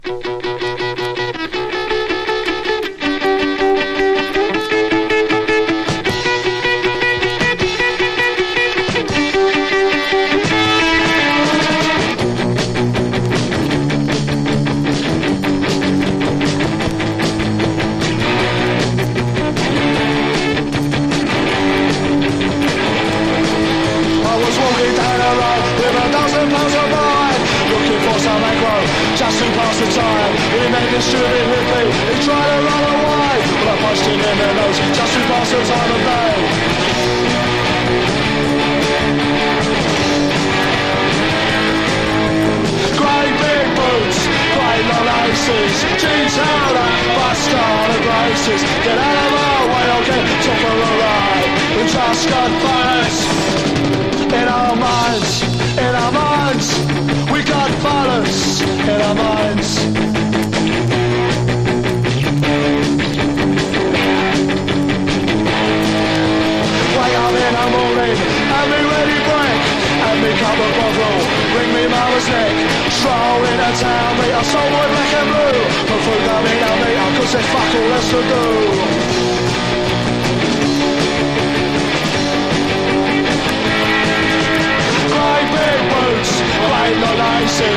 1. PUNK / HARDCORE >
勢い溢れるベスト・アルバム。